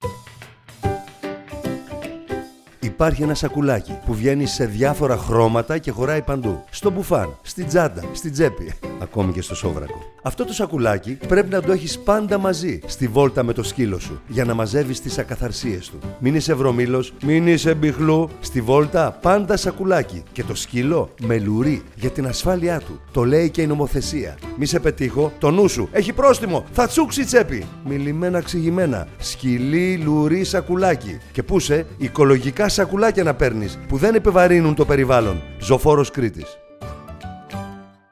Να ευχαριστήσουμε ολόψυχα τον απολαυστικό Γιώργο Γιαννόπουλο για την συμμετοχή του στο τηλεοπτικό και ραδιοφωνικό μας σποτ!
ραδιοφωνικο-Γιαννοπουλος-ΖΩΦΟΡΟΣ-λουρι-σκυλι-βολτα-1.mp3